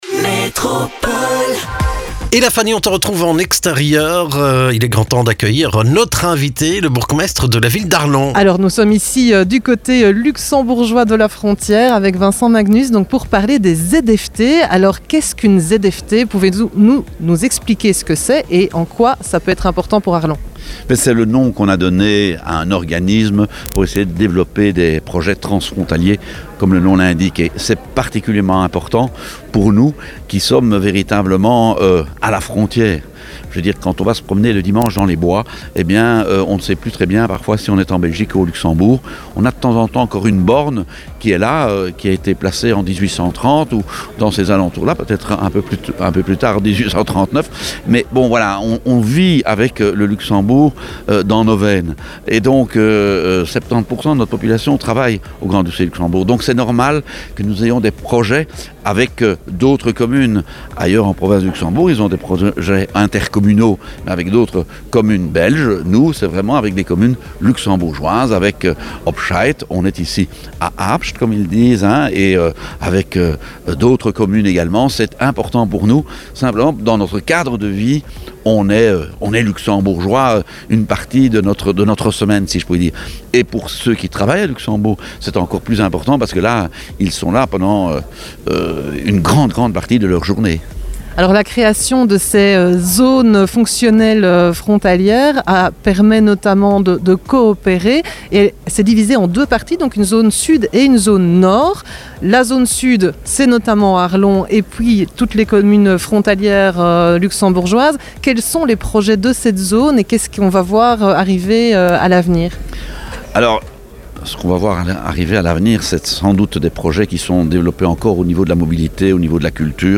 Le bourgmestre d'Arlon se réjouit de la création des Zones Fonctionnelles Tranfrontalières initiées par l'Europe. Une façon de récolter des subsides pour développer des projets locaux...